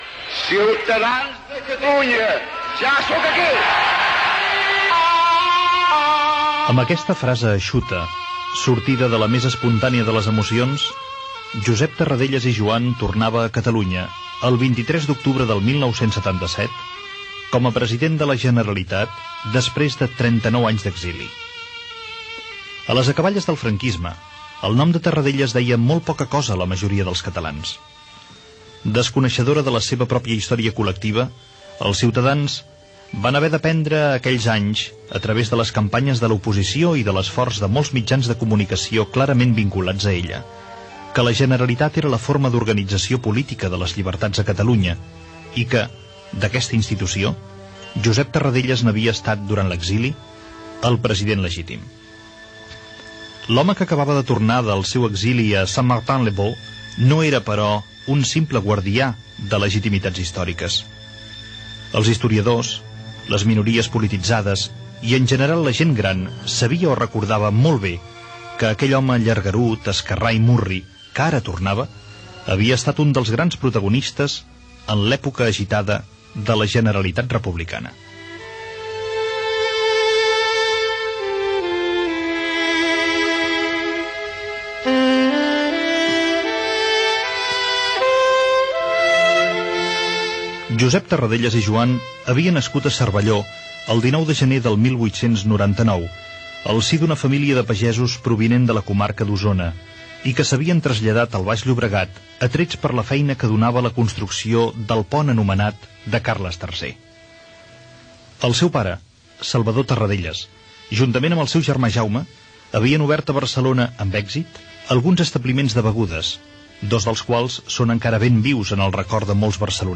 Perfil biogràfic del president de la Generalitat Josep Tarradellas amb motiu de la seva mort el dia 10 de juny Gènere radiofònic Informatiu Presentador/a Cuní, Josep